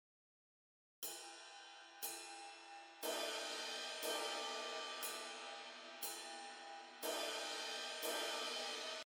ライドシンバル
ハイハットと同じく、リズムを刻むために使われるシンバルです。
ridecymbal.mp3